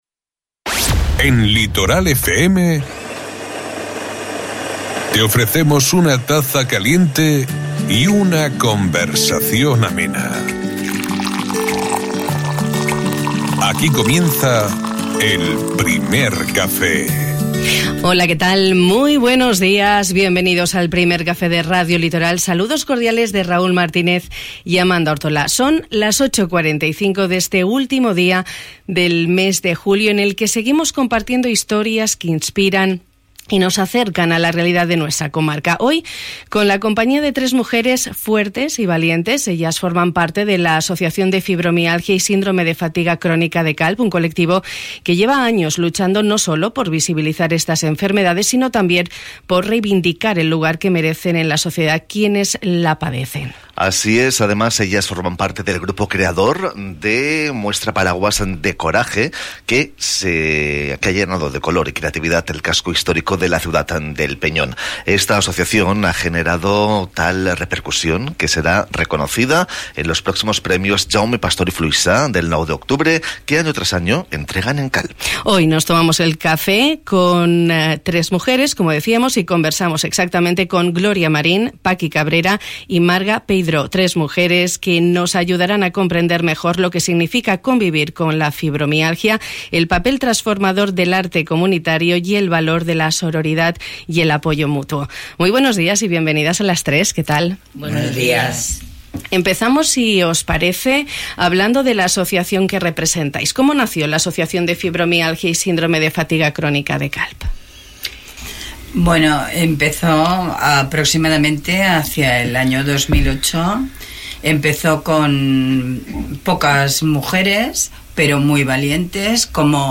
Hoy nos hemos tomado el Primer Café de Radio Litoral con tres mujeres fuertes y valientes